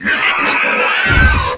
Pig_Die_00.wav